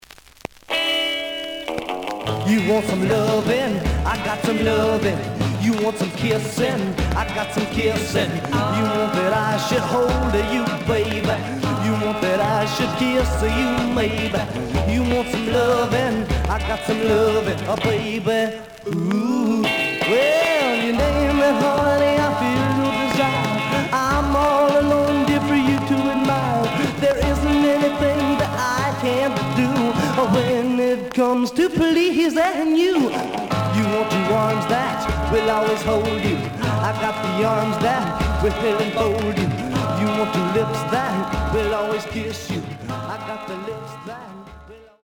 The audio sample is recorded from the actual item.
●Genre: Rhythm And Blues / Rock 'n' Roll
Some click noise on beginnig of B side due to scratches.